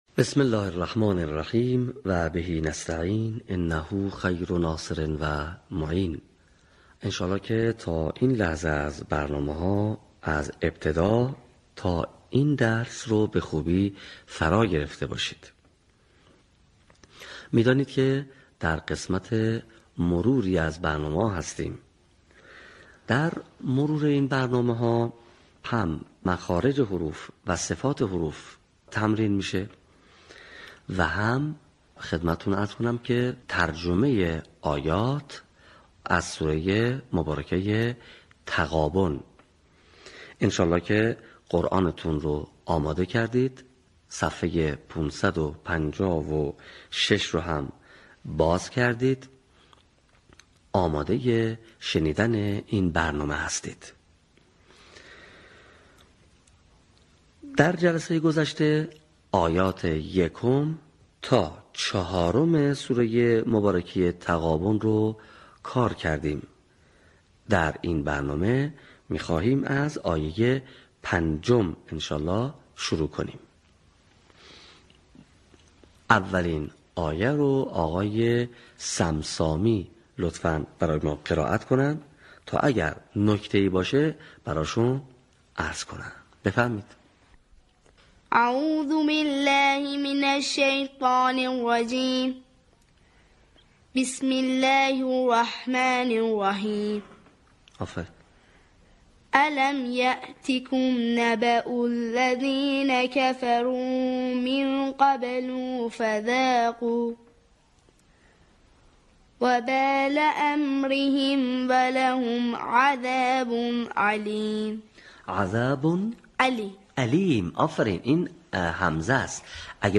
صوت | آموزش قرائت آیات ۵ تا ۱۳ سوره تغابن
به همین منظور مجموعه آموزشی شنیداری (صوتی) قرآنی را گردآوری و برای علاقه‌مندان بازنشر می‌کند.